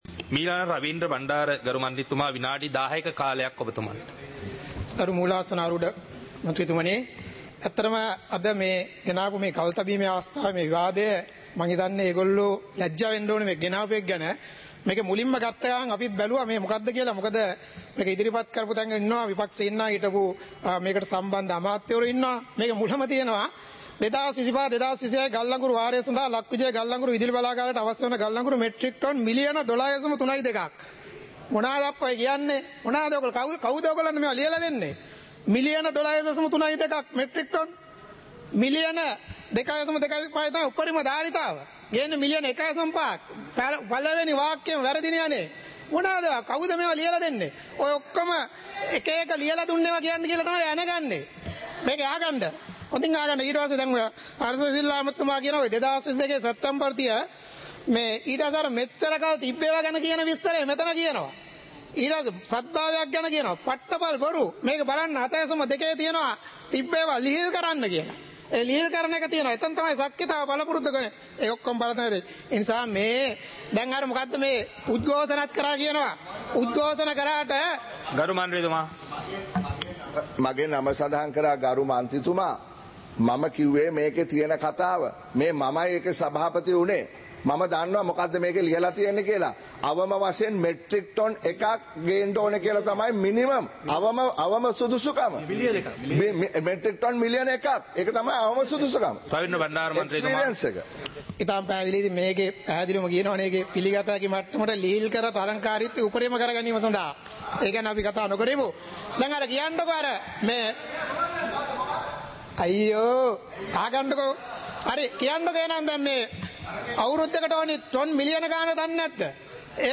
ශ්‍රී ලංකා පාර්ලිමේන්තුව - සභාවේ වැඩ කටයුතු (2026-02-20)
පාර්ලිමේන්තුව සජීවීව - පටිගත කළ